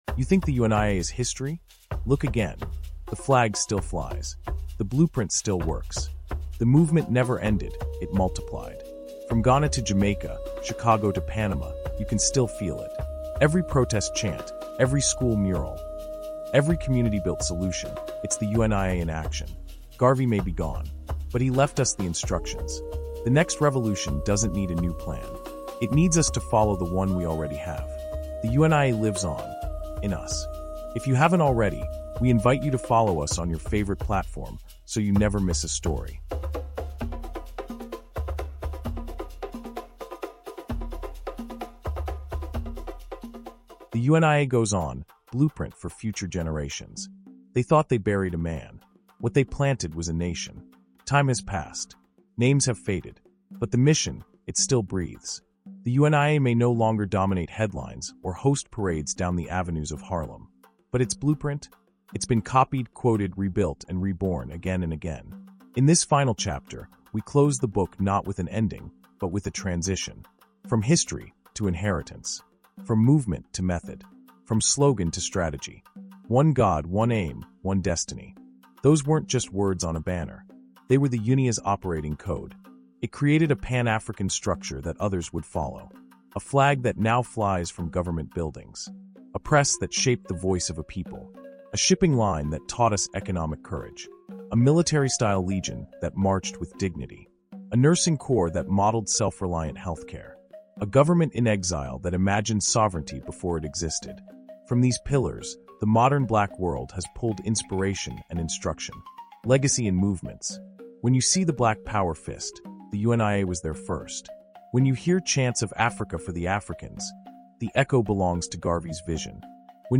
UNIA: Blueprint for Future Generations Audiobook